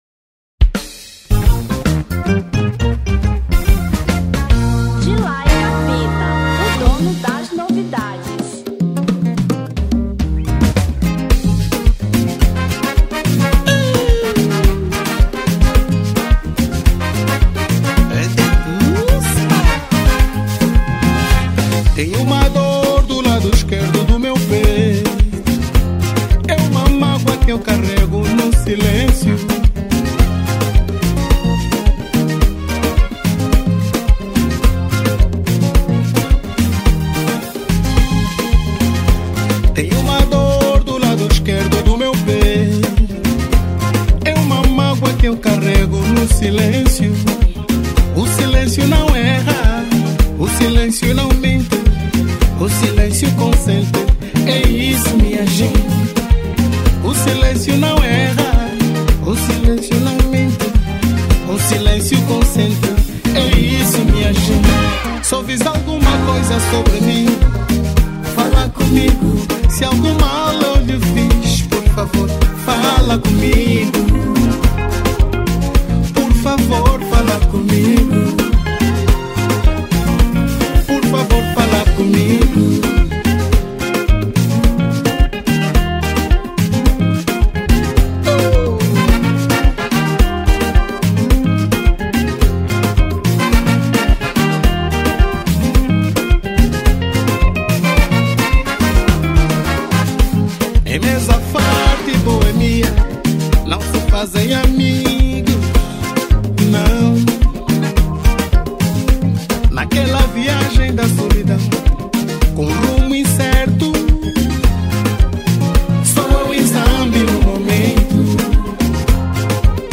Gênero: Semba